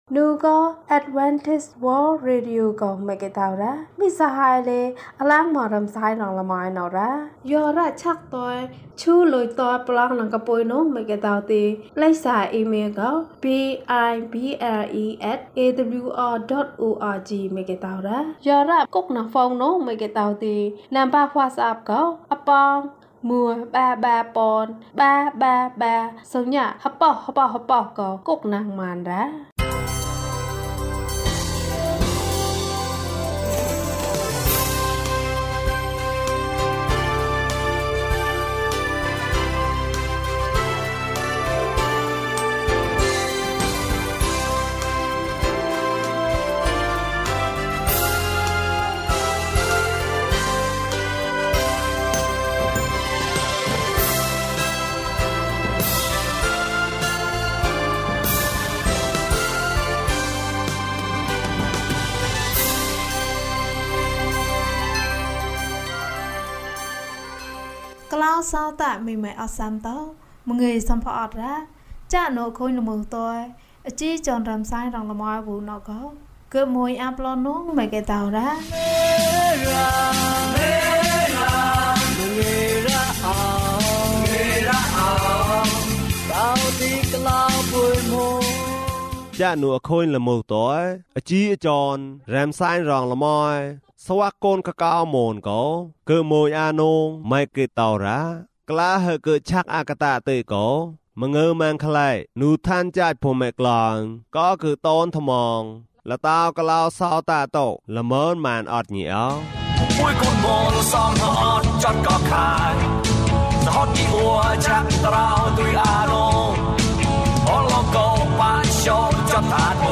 ခရစ်တော်ထံသို့ ခြေလှမ်း ၁၆။ ကျန်းမာခြင်းအကြောင်းအရာ။ ဓမ္မသီချင်း။ တရားဒေသနာ။